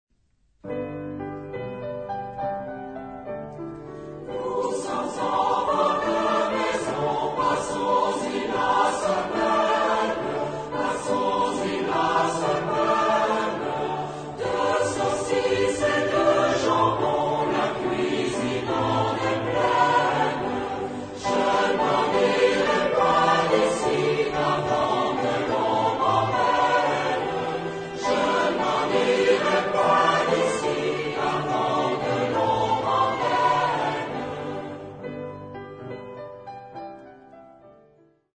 Genre-Style-Form: Secular ; Popular ; Song with repetition
Mood of the piece: moderate
Type of Choir: SATB  (4 mixed voices )
Tonality: G major
Origin: Vendée (F)